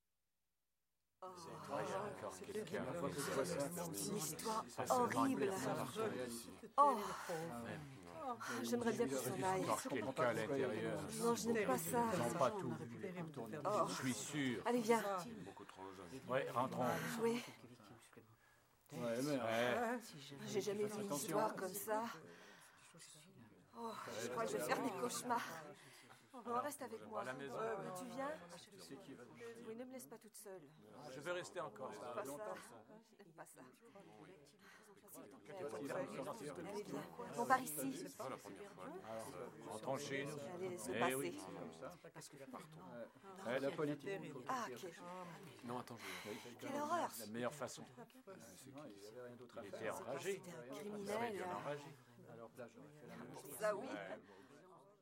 描述：集体叫好声。(录音室法语配音的未用材料)
标签： 氛围 对话 法语 现货 声乐
声道立体声